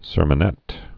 (sûrmə-nĕt)